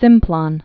(sĭmplŏn, săɴm-plôɴ)